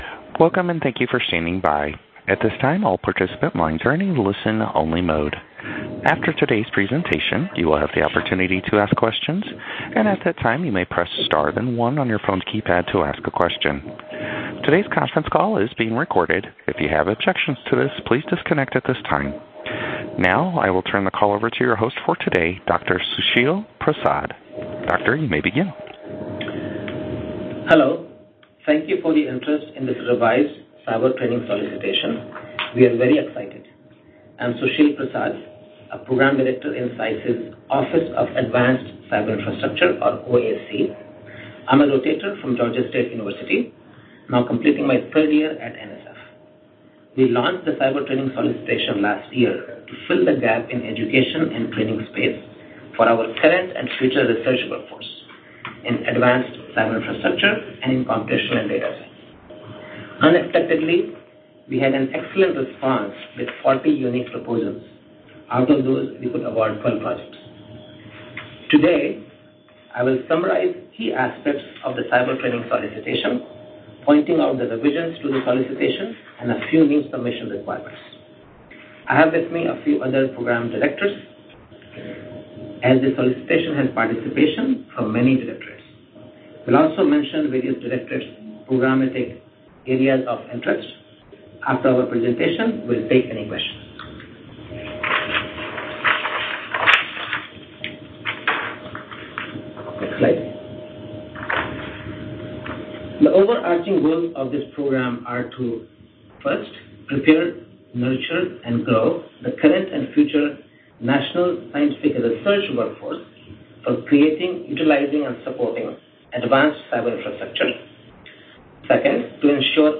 Training-based Workforce Development for Advanced Cyberinfrastructure (CyberTraining) Webinar